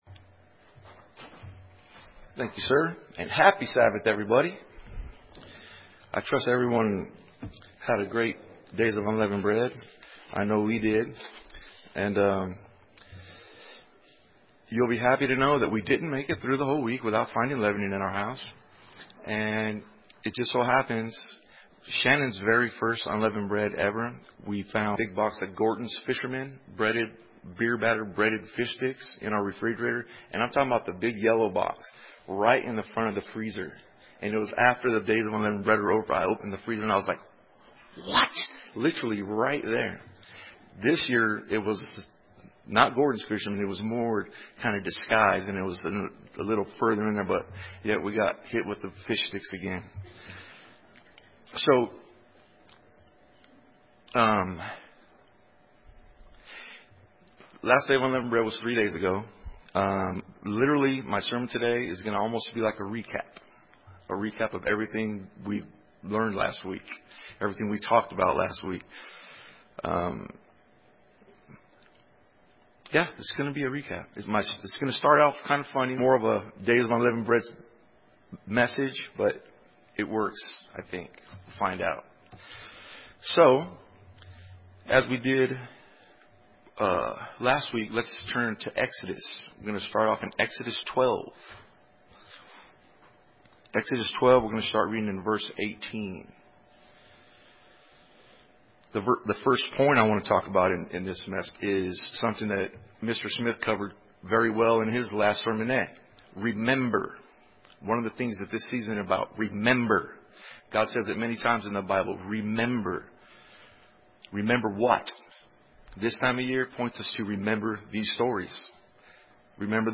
This sermon will discuss some of those actions and our need to continue those actions all year long.